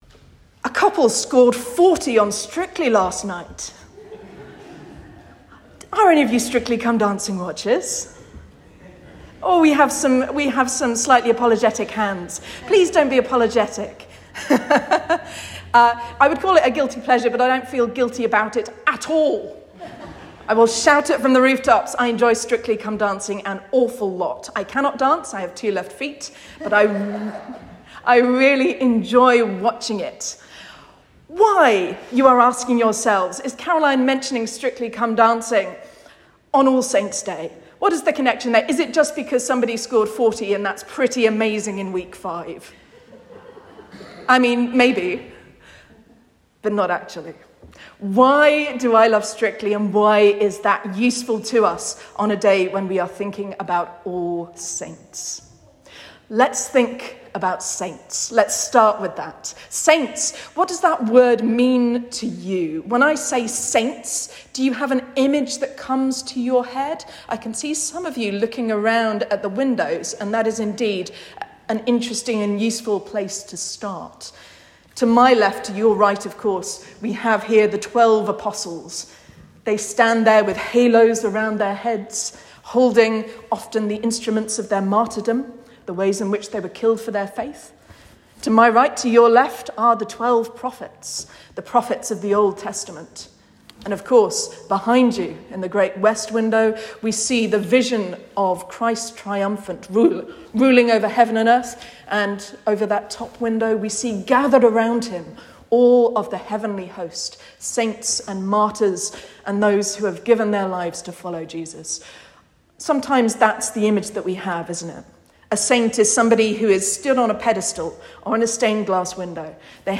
Sermon and Readings for Sunday 2nd November 2025